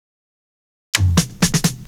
Fill 128 BPM (11).wav